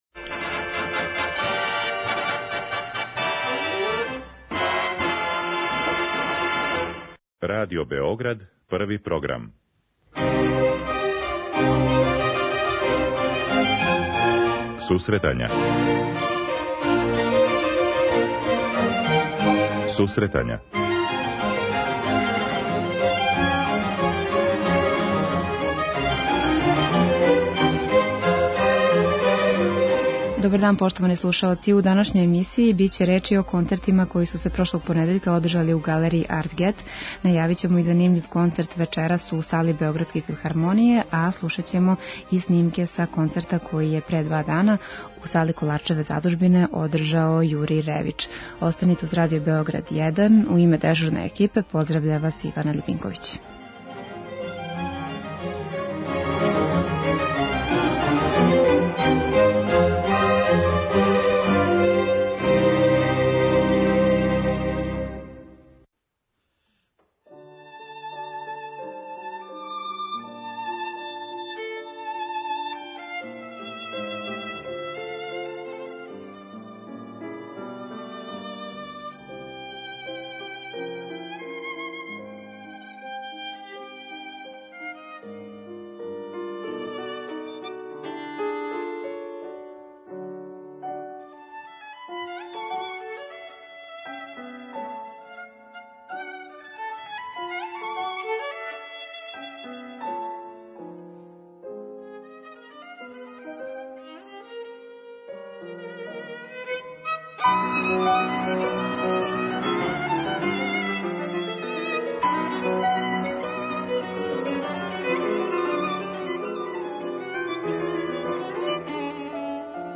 преузми : 10.78 MB Сусретања Autor: Музичка редакција Емисија за оне који воле уметничку музику.